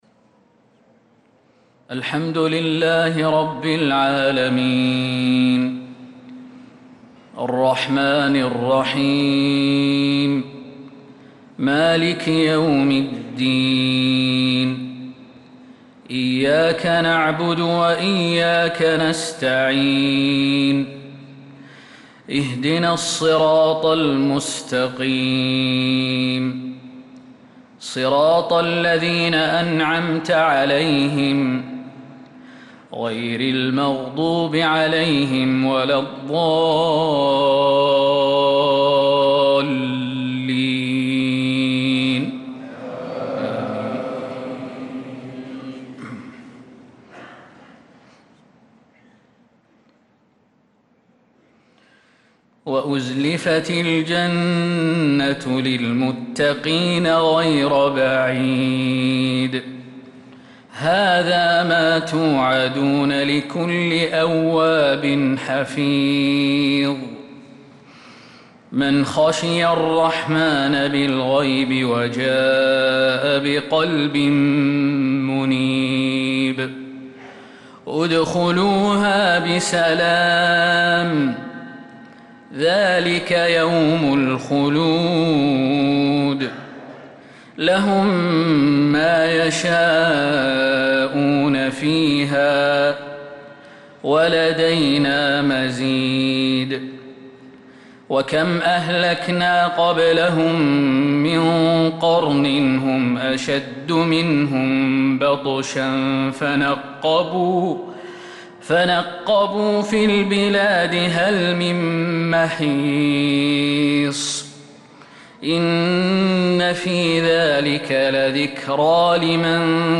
صلاة المغرب للقارئ خالد المهنا 13 ذو القعدة 1445 هـ
تِلَاوَات الْحَرَمَيْن .